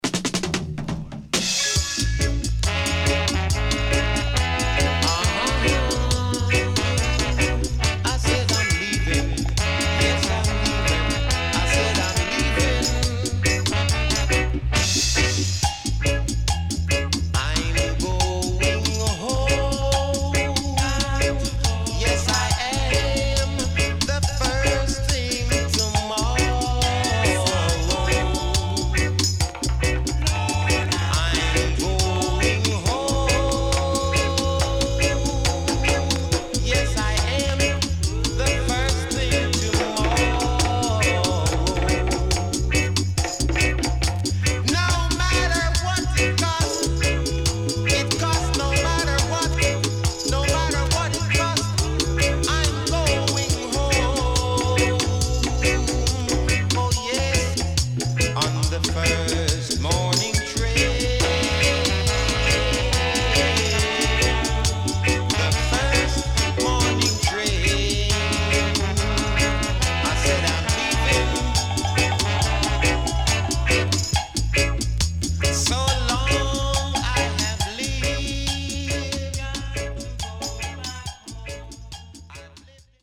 CONDITION SIDE A:VG+
SIDE A:少しチリノイズ入りますが良好です。